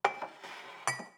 SFX_Cup_PutAway_01.wav